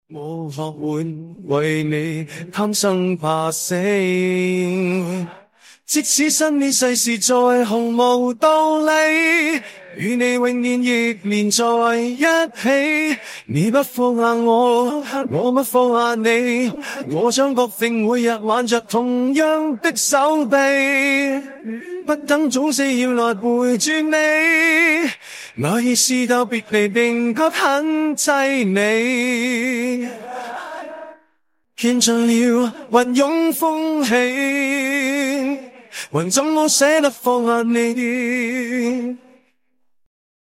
因为原声有噪声，所以导致克隆后也存在一些杂音，提高原声的质量即可解决